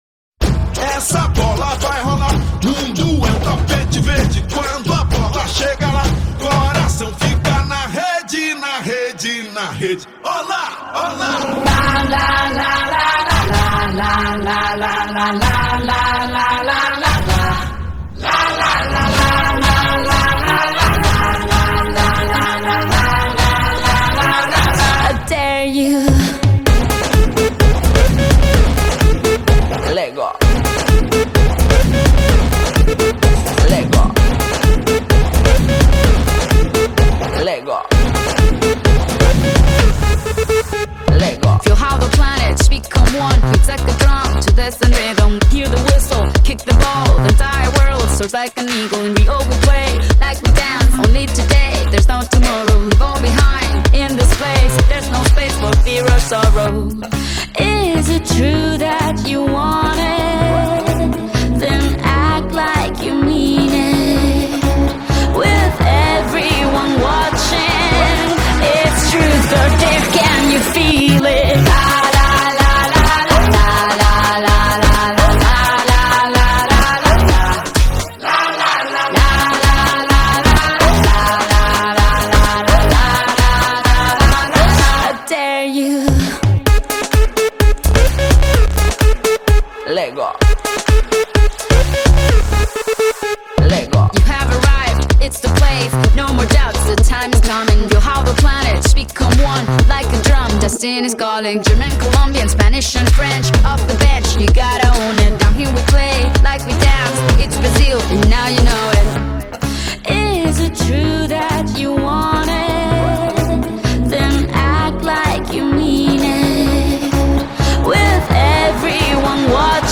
one of the most vibrant and energetic anthems
pop song
exotic, rhythmic, and unapologetically fun